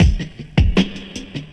Track 11 - Drum Break 01.wav